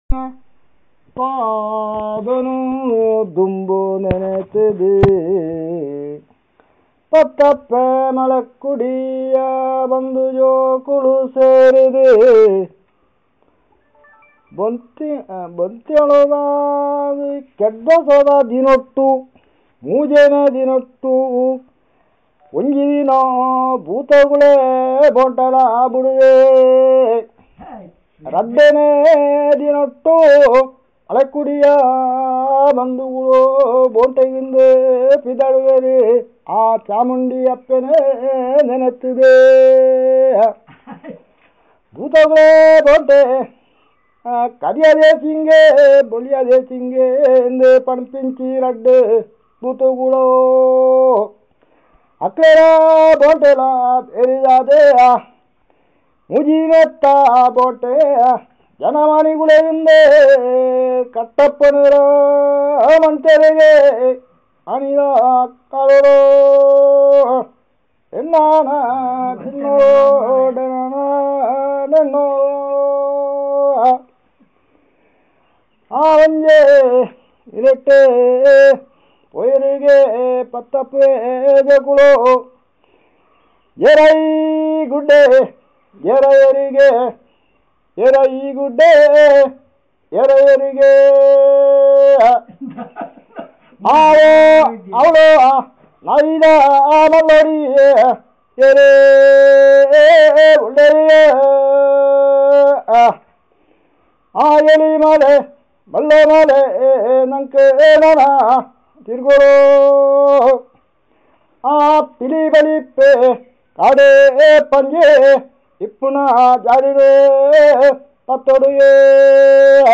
Performance of traditional song and description of hunting practice